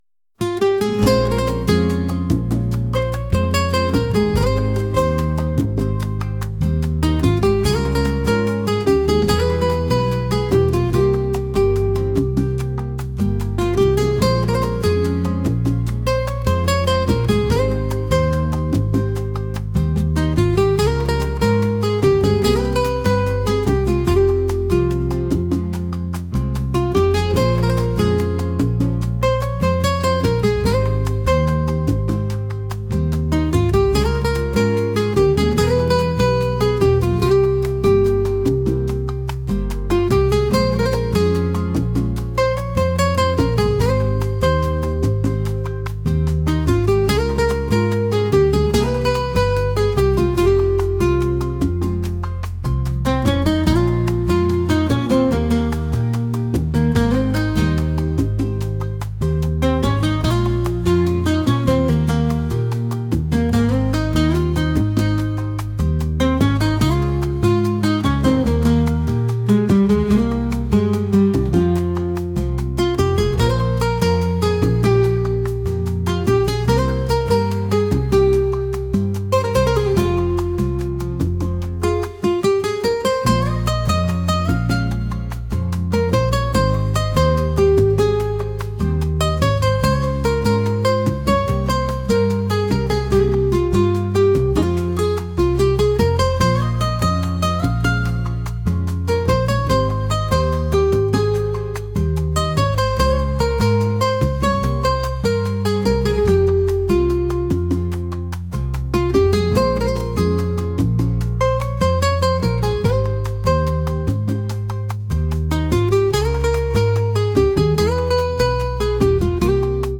latin | acoustic | soulful